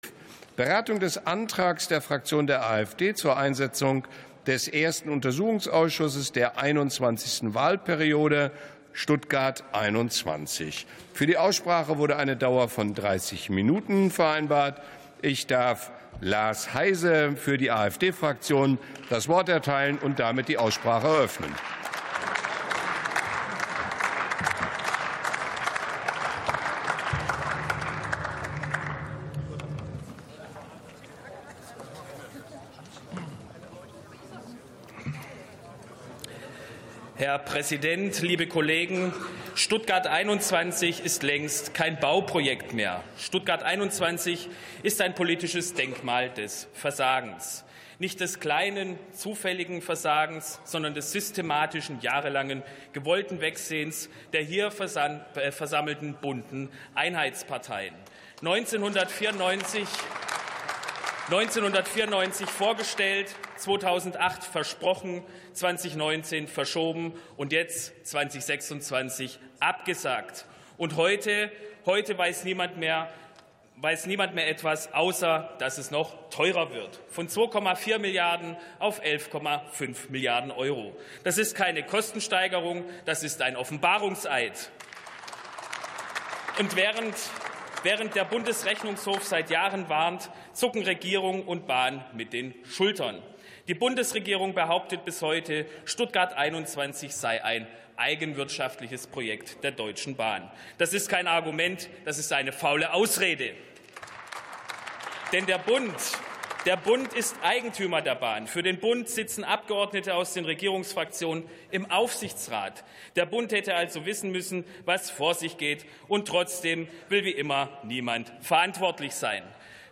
63. Sitzung vom 06.03.2026. TOP ZP 11: Einsetzung des 1. UA ("Stuttgart 21") ~ Plenarsitzungen - Audio Podcasts Podcast